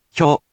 In romaji, 「ひょ」 is transliterated as 「hyo」which sounds sort of like 「hyohh」.